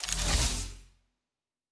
Door 3 Close 2.wav